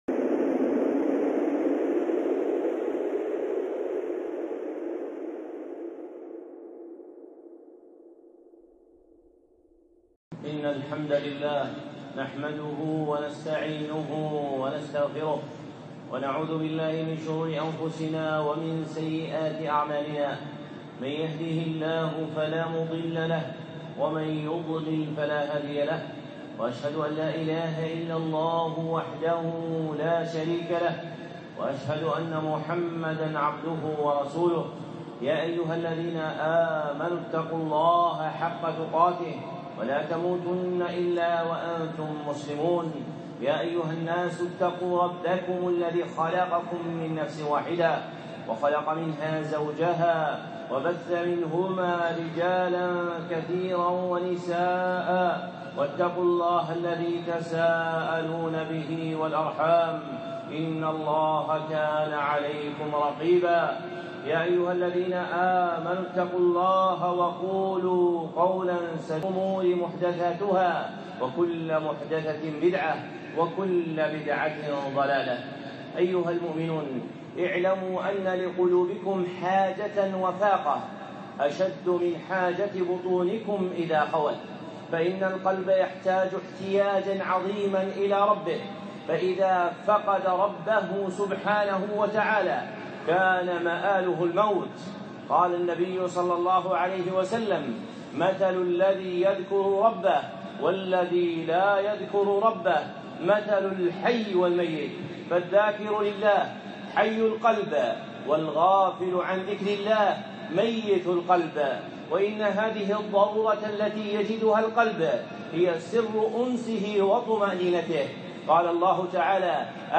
خطبة (فاقة القلوب)